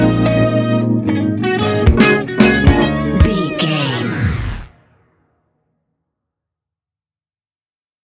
Fast paced
In-crescendo
Uplifting
Ionian/Major
D♯
instrumentals